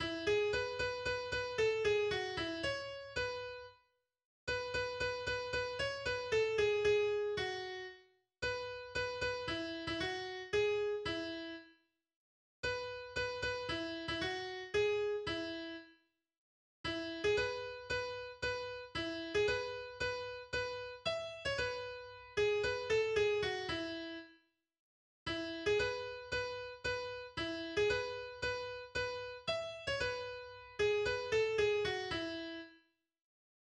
Volksweise